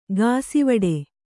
♪ gāsivaḍe